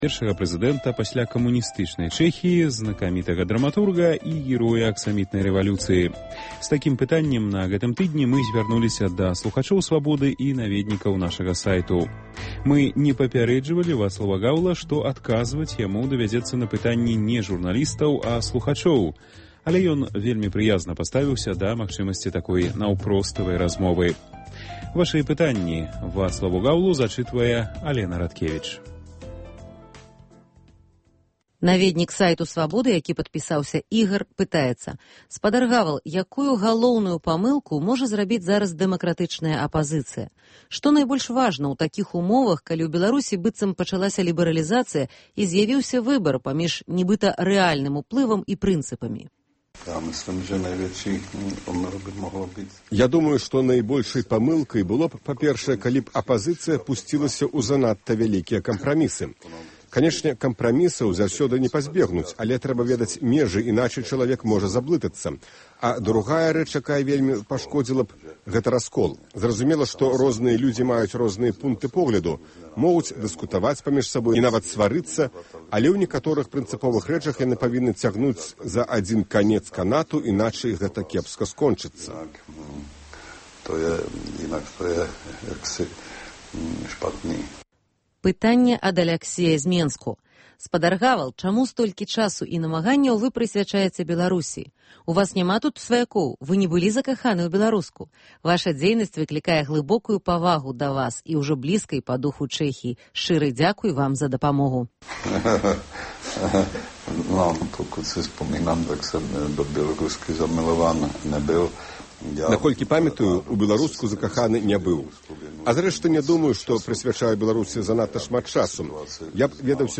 Першы прэзыдэнт Чэхіі Вацлаў Гавал адказвае на пытаньні наведнікаў нашага сайту, слухачоў і журналістаў Радыё Свабода.